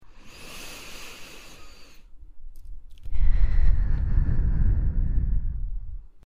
Real human voice
Breathe.mp3